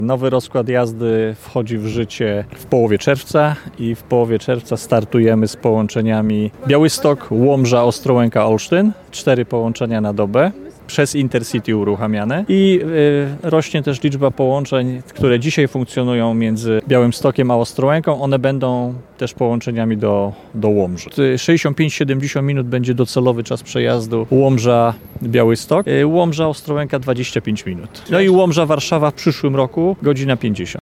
Początkowo pociągi z Łomży do Białegostoku będą jeździły przez godzinę i 20 minut, ale docelowo czas będzie krótszy – zapewnił wiceminister infrastruktury Piotr Malepszak.